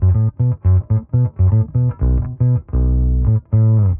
Index of /musicradar/dusty-funk-samples/Bass/120bpm
DF_PegBass_120-B.wav